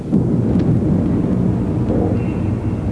Thunder Rumbling Sound Effect
Download a high-quality thunder rumbling sound effect.
thunder-rumbling.mp3